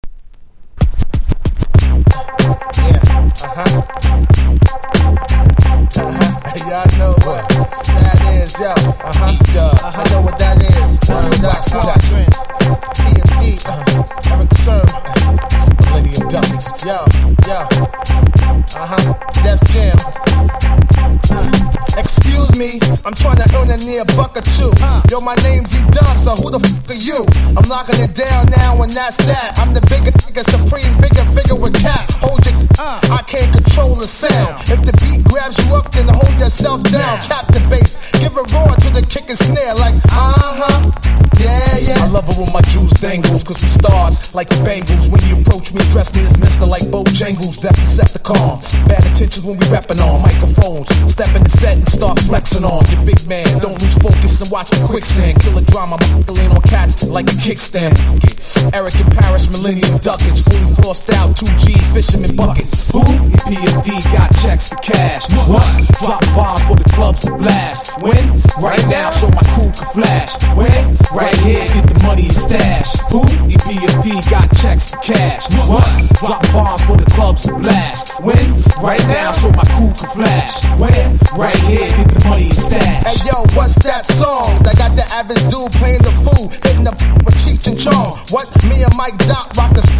バイオリン風の弦音のフレーズが脳裏に焼きつく